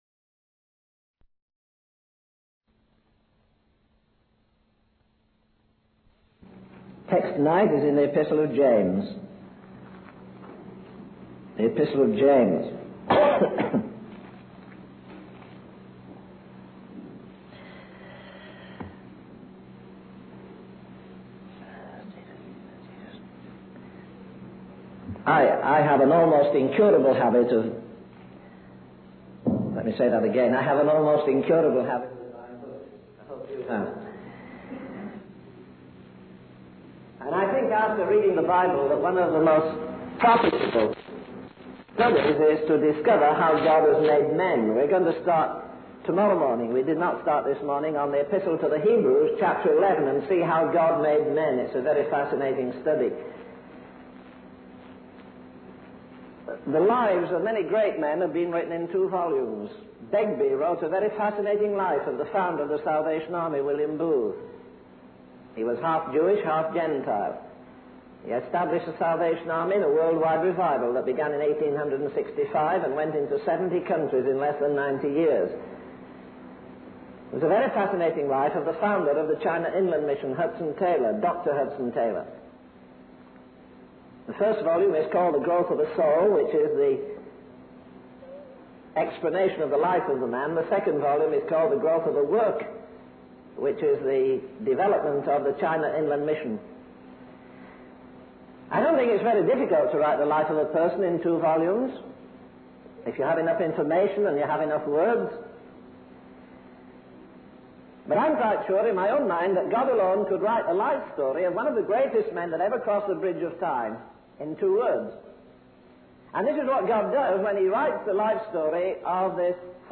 In this sermon, the speaker discusses the story of Moses and how God called him to the backside of the desert despite his impressive qualifications and accomplishments. The speaker also shares the story of Hudson Taylor, a missionary in China, who dedicated his life to prayer and saw incredible results. The sermon emphasizes the power of prayer and the importance of seeking God's will above all else.